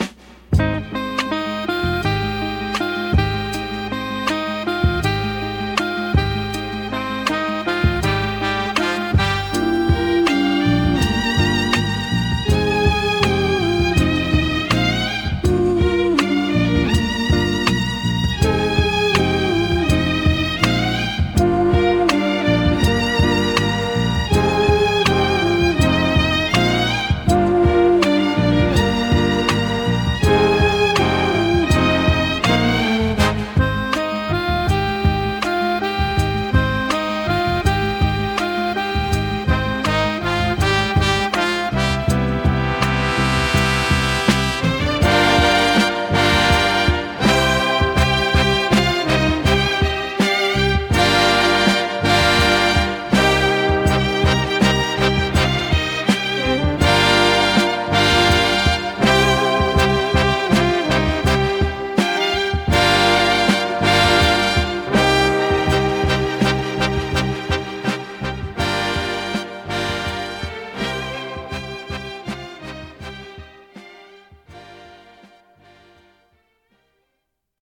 Mp3 demos of a few below.(Wav files will be better quality)